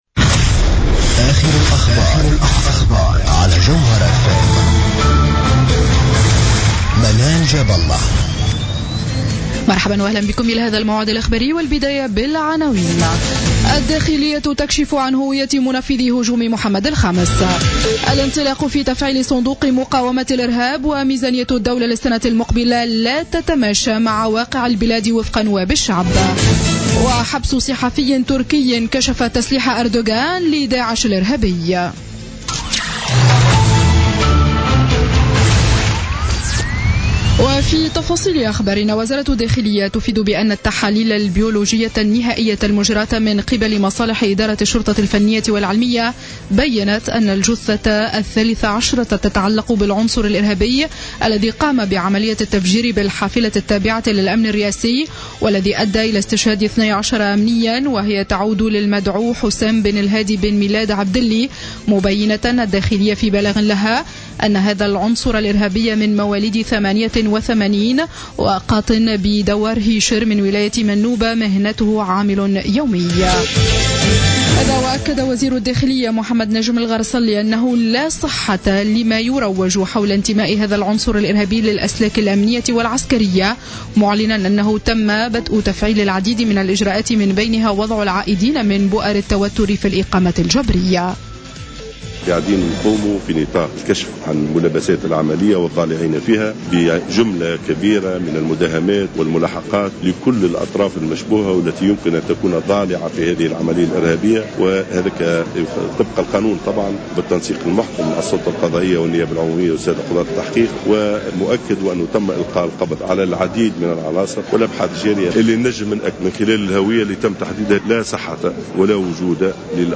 نشرة أخبار منتصف الليل ليوم الجمعة 27 نوفمبر 2015